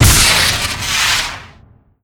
Add ghost sounds.
disappear.LN50.pc.snd.wav